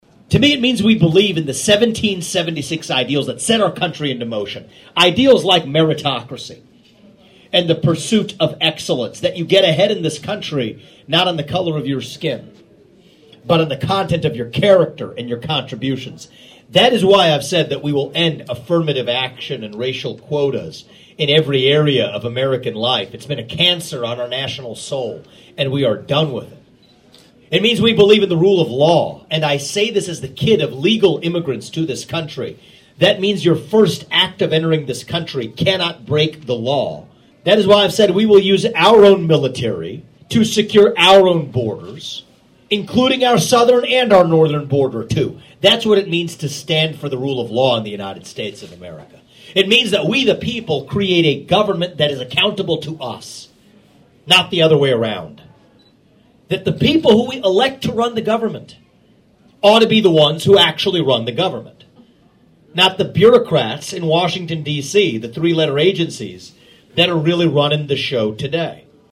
Presidential Candidate Vivek Ramaswamy Touts Term Limits During a Campaign Stop in Atlantic